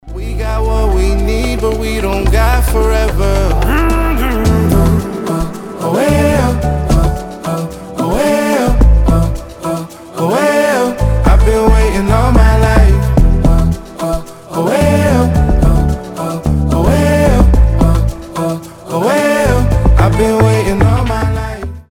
• Качество: 320, Stereo
легкие